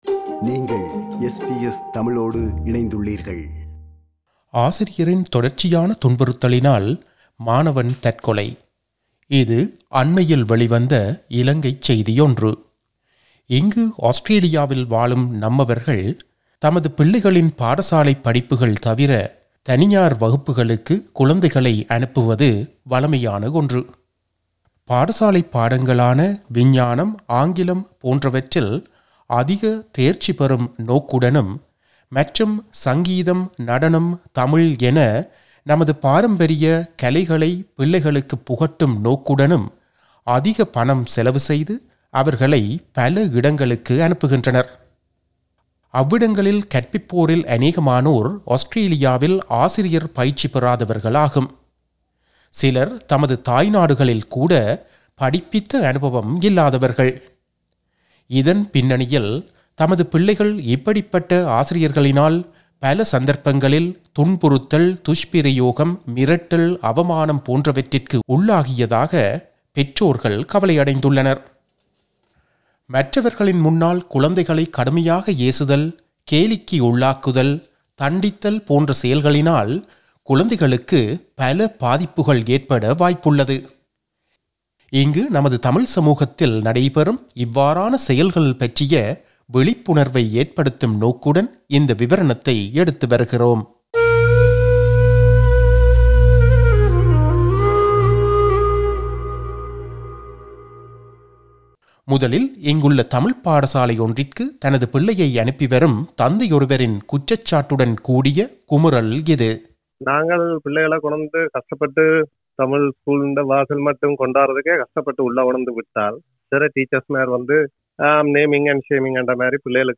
பாதிக்கப்பட்ட இரு தமிழ்ப் பெற்றோர்கள்